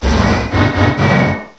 sovereignx/sound/direct_sound_samples/cries/regidrago.aif at master